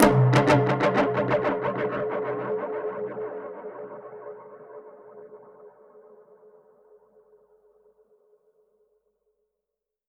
Index of /musicradar/dub-percussion-samples/95bpm
DPFX_PercHit_B_95-01.wav